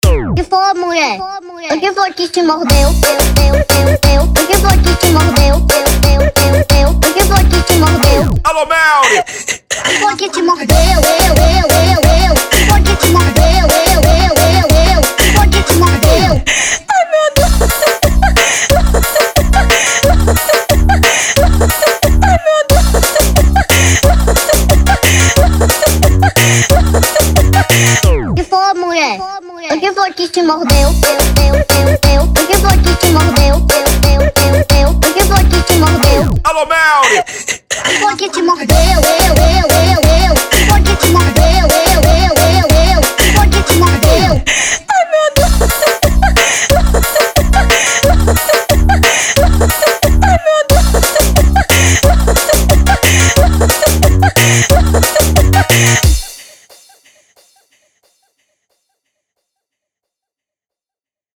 Tecnofunk